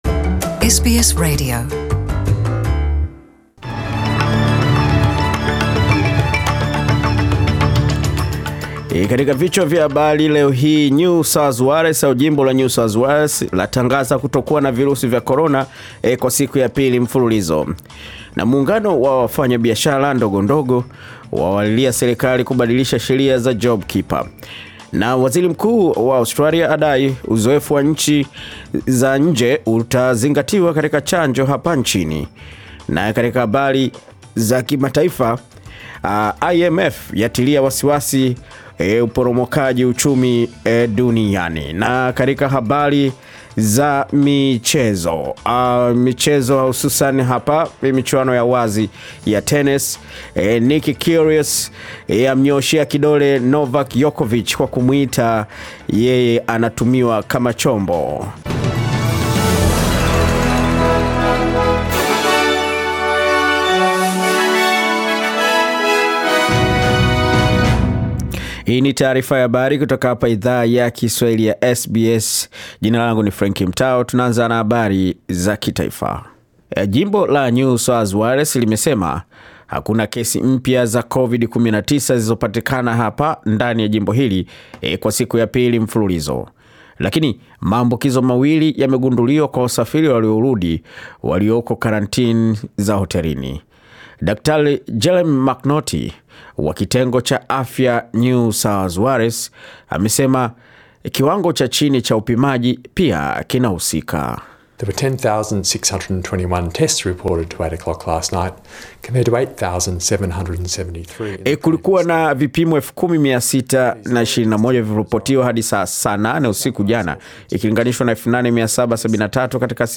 Taarifa ya habari 19 Januari 2021